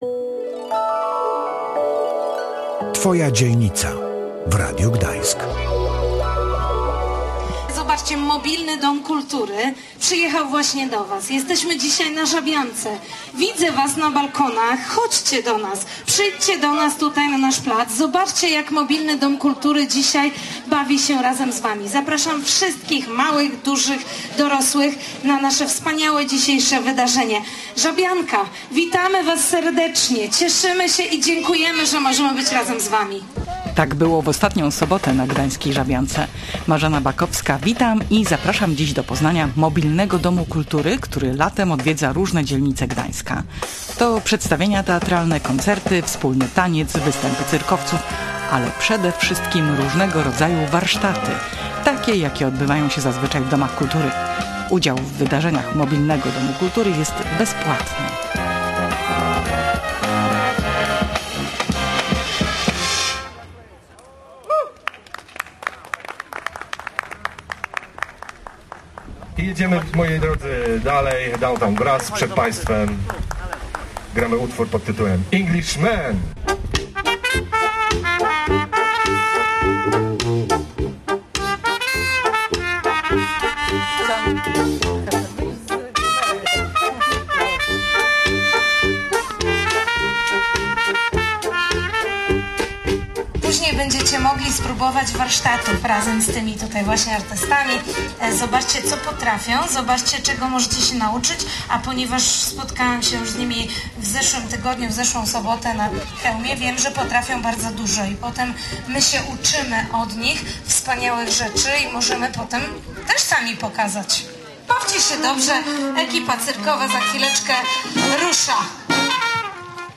uczestnicy warsztatów i mieszkańcy dzielnicy.